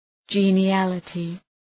Shkrimi fonetik {,dʒi:nı’ælətı}